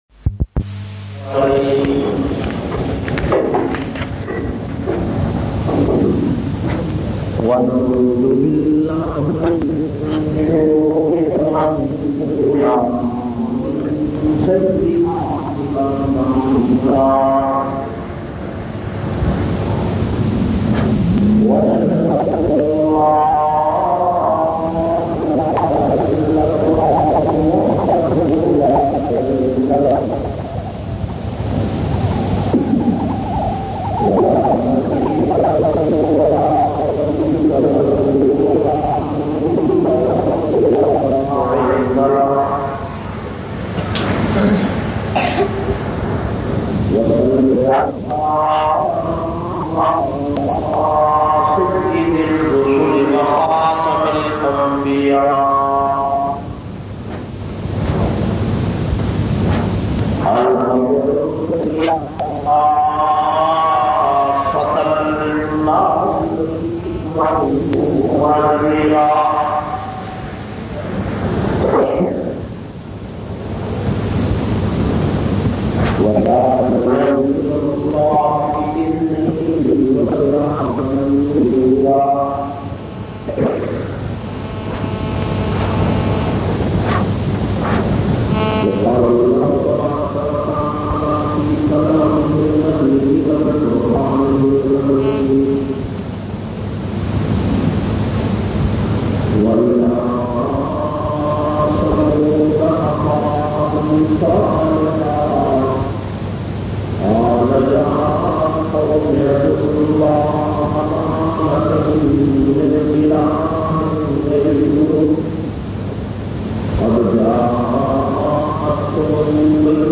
485- Hazrat Saleh A.S khutba Jumma Jamia Masjid Muhammadia Samandri Faisalabad.mp3